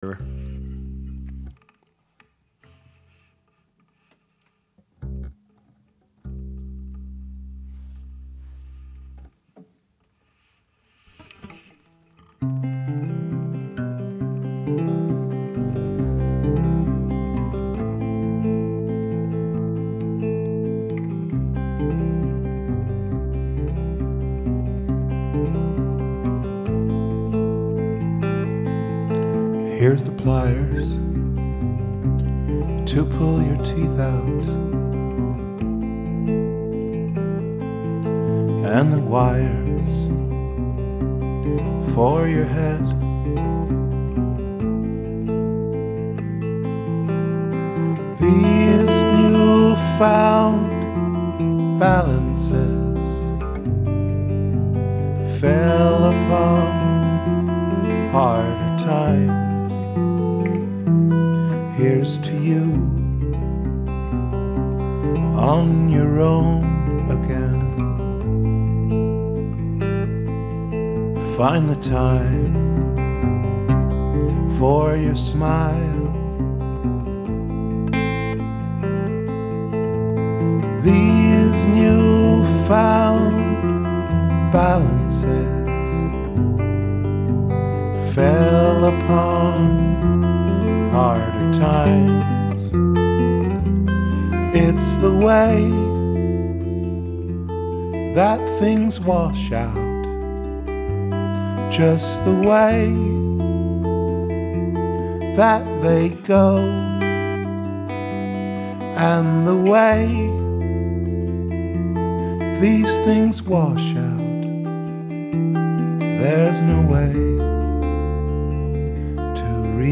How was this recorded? Rehearsals 20.2.2012